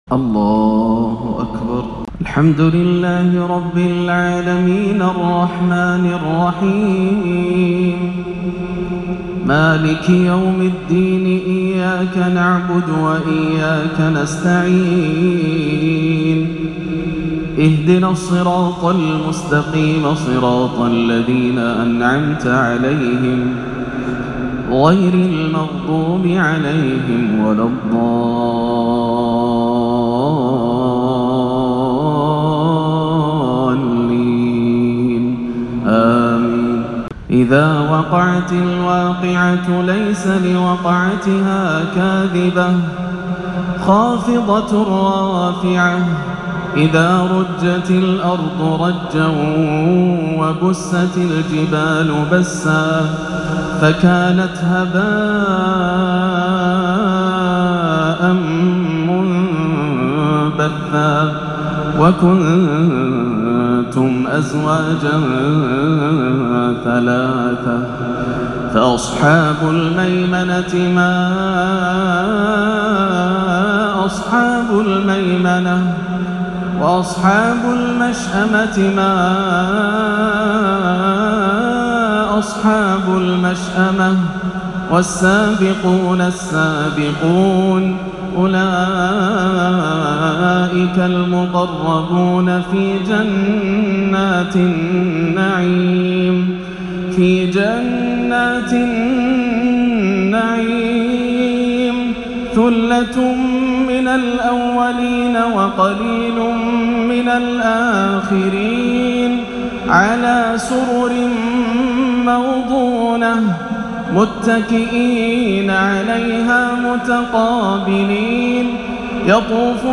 تلاوة لسورة الواقعة حبّر وأبحر فيها عشاء 3-2-1439 > عام 1439 > الفروض - تلاوات ياسر الدوسري